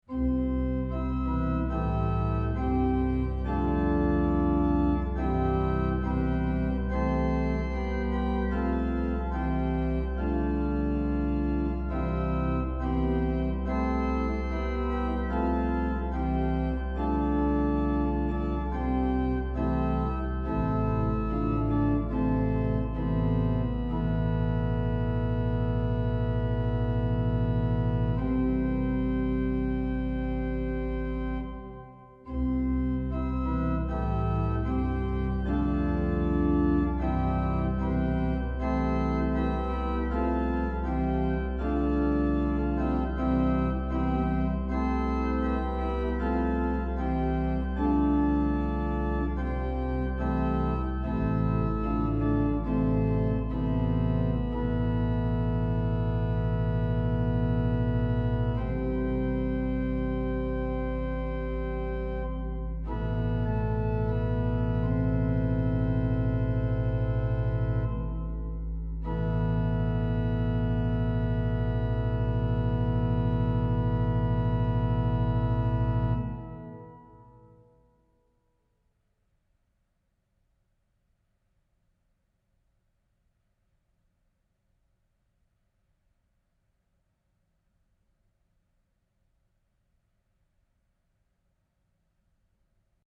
Solo Organ